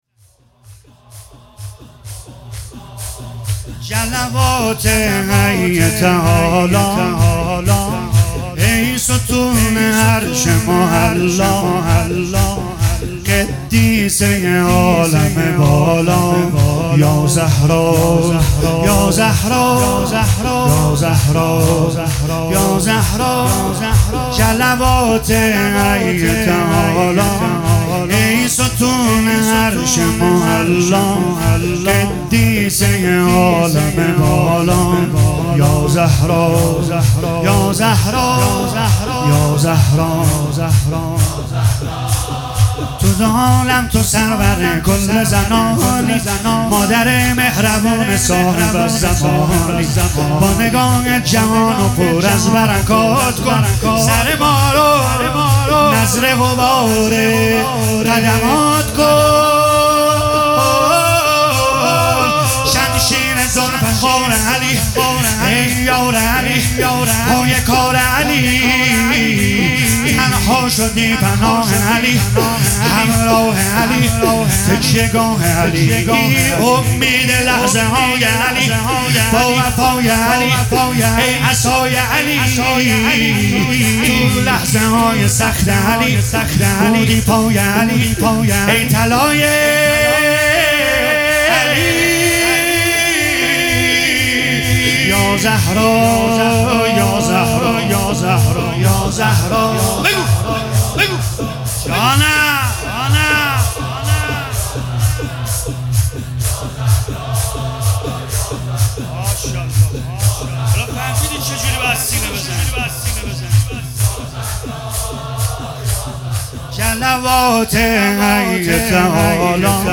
♦مراسم عزارداری شهادت حضرت زهرا سلام الله علیها